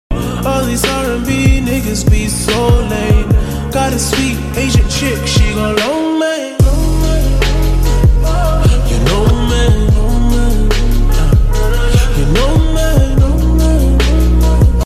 Lamborghini Gallardo Spyder. Sound Effects Free Download
Lamborghini Gallardo Spyder. sound effects free download